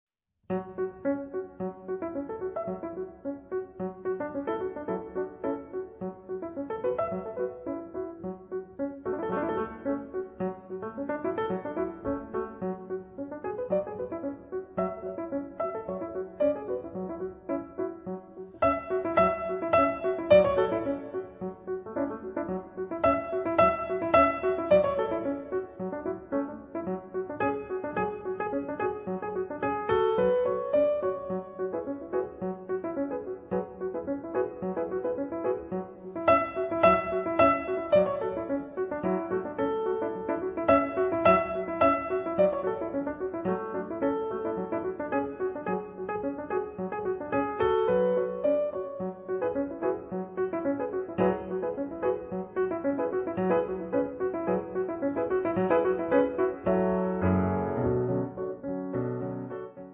pianoforte
la destra diventa dispensatrice di swing